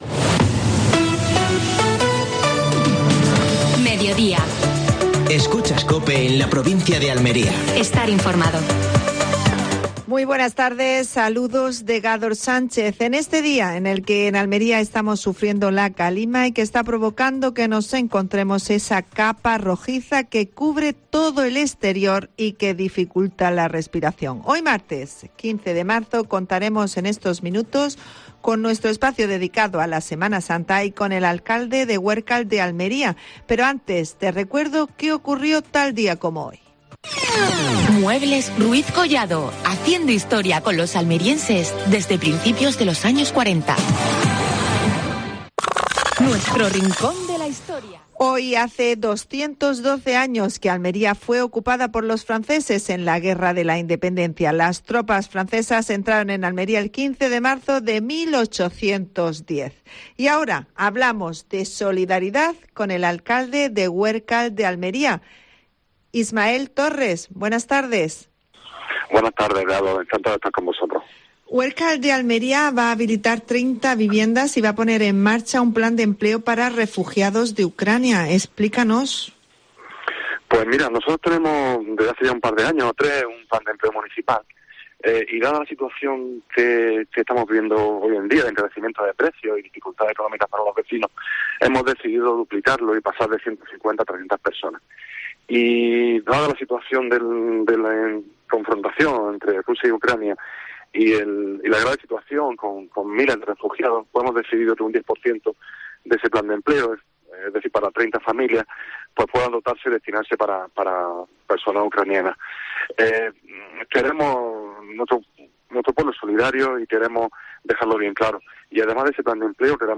AUDIO: Actualidad en Almería. Entrevista a Ismael Torres (alcalde de Huércal de Almería).